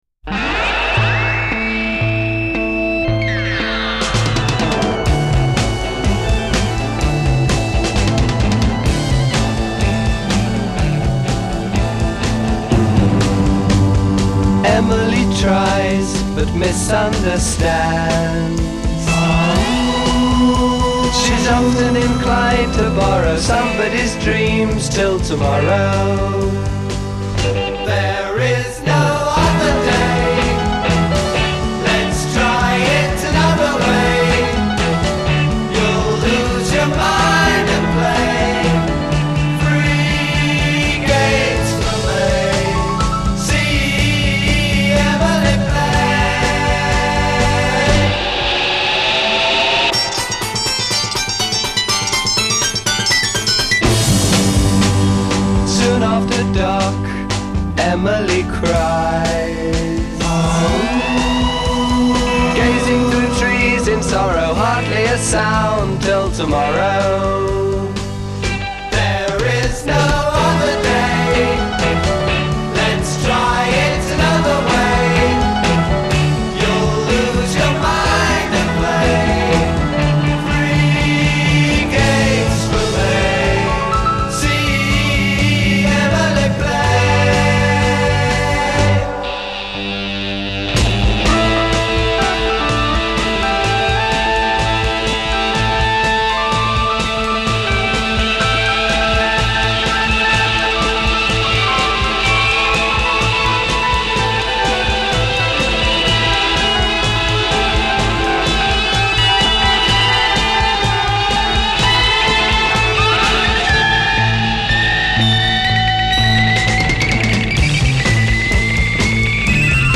A Verse   Double-tracked solo vocal. a
Refrain   Unison vocals with harmony at the ends of lines. b
Fill   sped up piano, guitar, bass, drums track *
Coda   Sustain from refrain and fade.
Psychedelic Pop